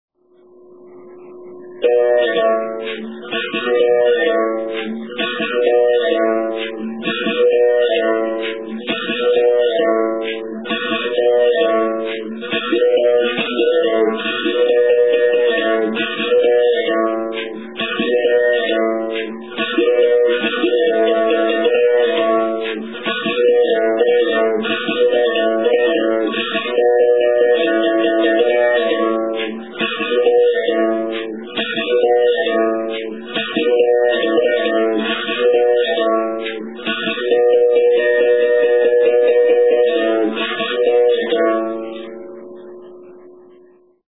Совершая удары бакетой по струне и периодически прижимая монету к последней, беримбау может издавать три основных звука: открытый (тон), закрытый (тин) и жужжащий звук слегка прижатой к струне монеты (чи).